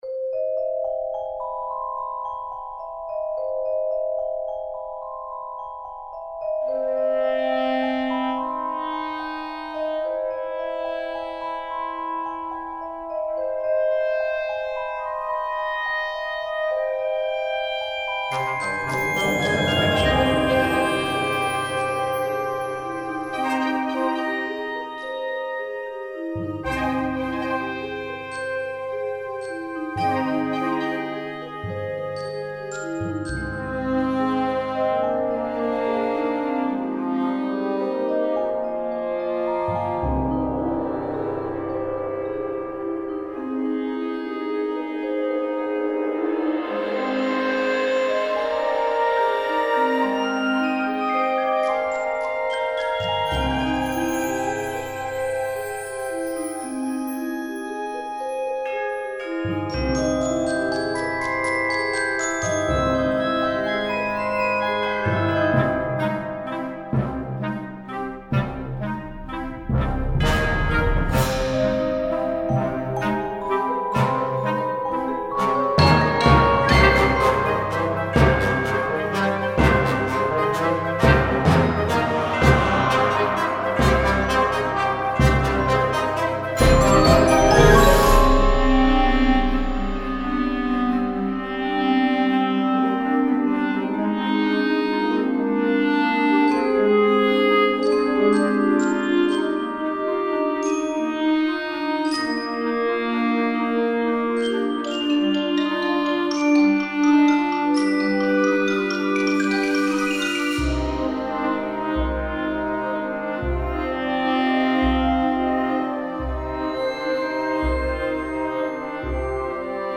written for concert band performance